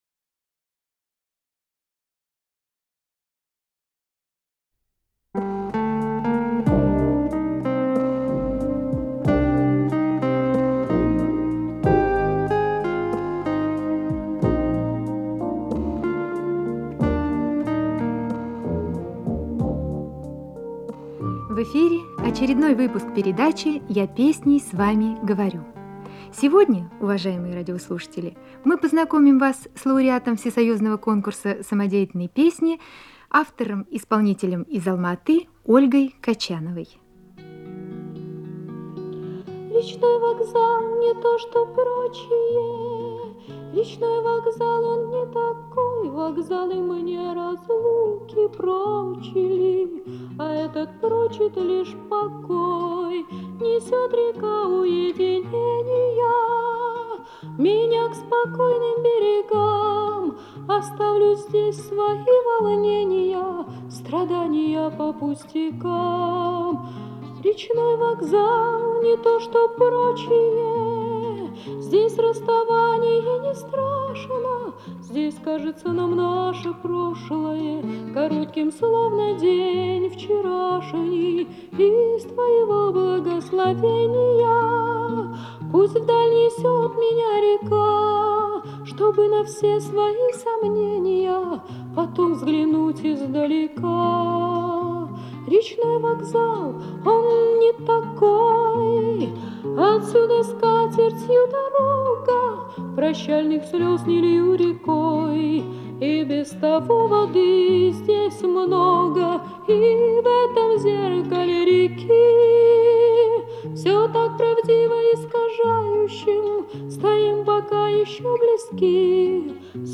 Интервью и песни